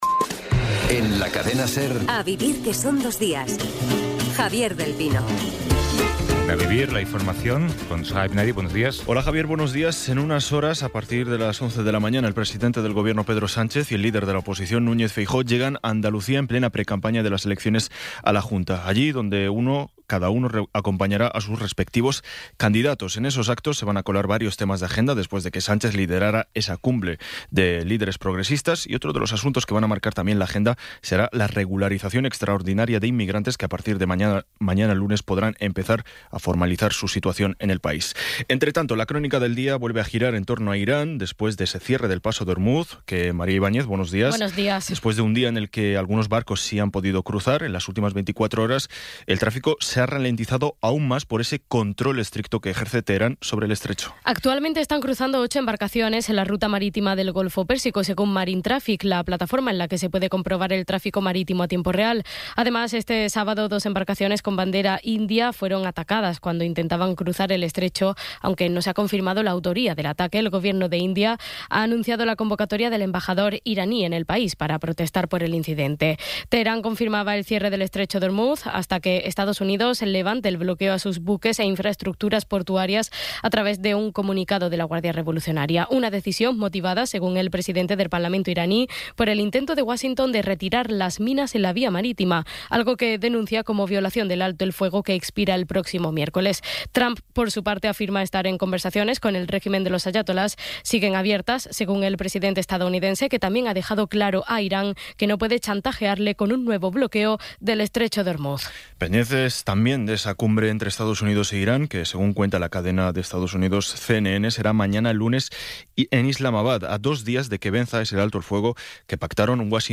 Resumen informativo con las noticias más destacadas del 19 de abril de 2026 a las nueve de la mañana.